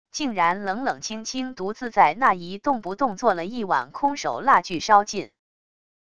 竟然冷冷清清独自在那一动不动坐了一晚空守蜡炬烧尽wav音频生成系统WAV Audio Player